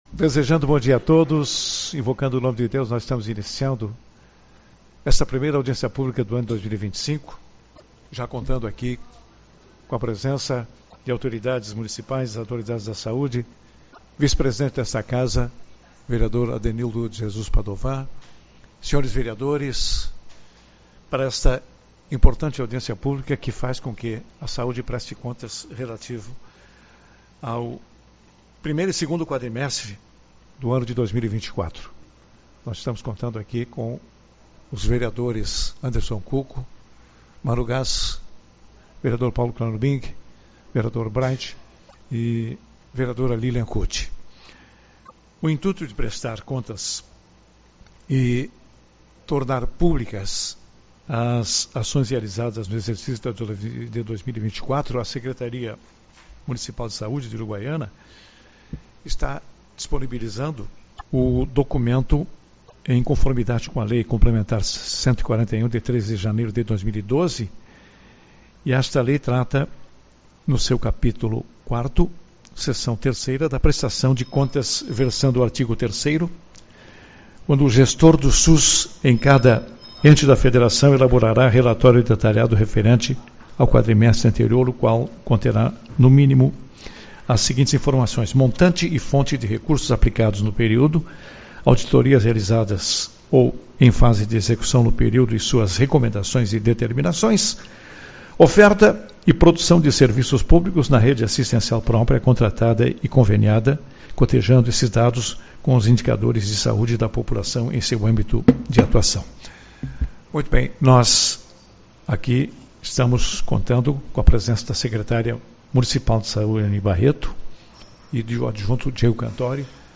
Audiência Pública-Prest. Ctas SUS